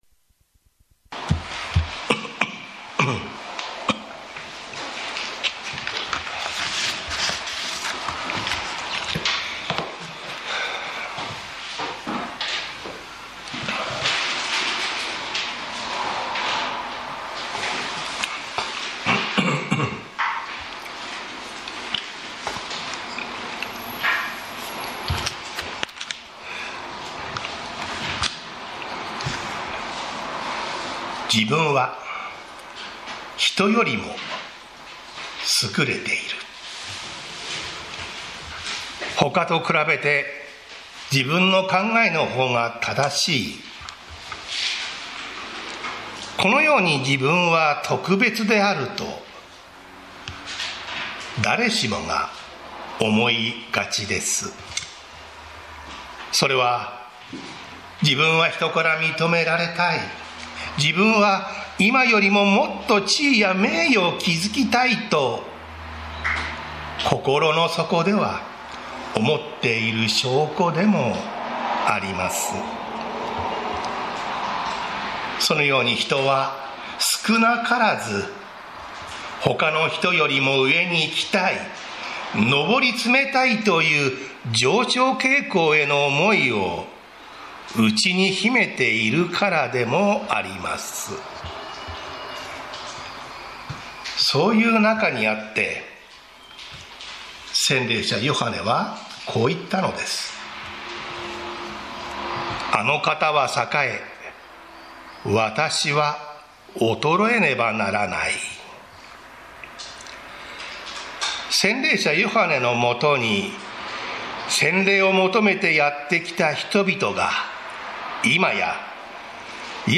日曜 朝の礼拝